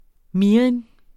Udtale [ ˈmiːʁin ] eller [ miˈʁiˀn ]